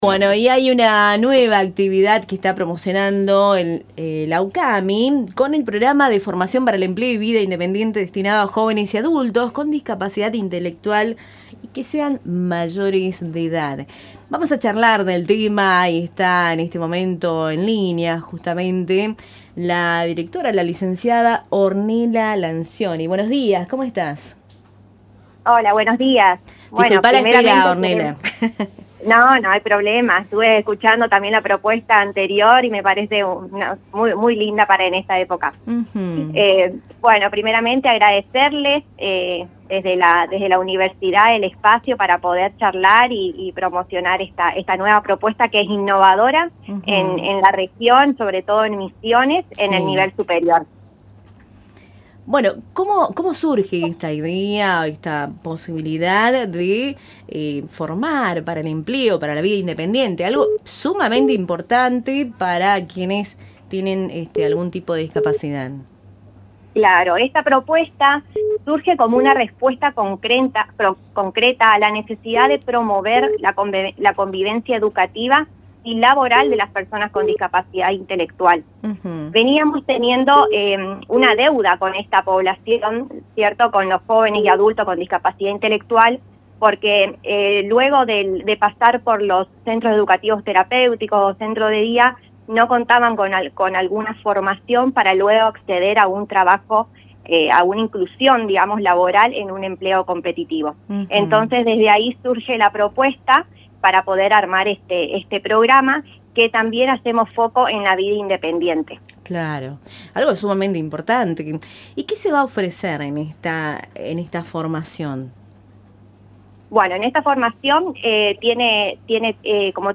Educación